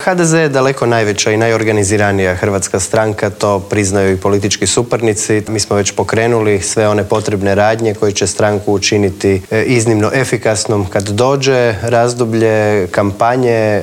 ZAGREB - Dan nakon raspuštanja 10. saziva Sabora i uoči odluke predsjednika Zorana Milanovića da će se parlamentarni izbori održati u srijedu 17. travnja, u Intervjuu tjedna Media servisa gostovao je predsjednik Sabora Gordan Jandroković.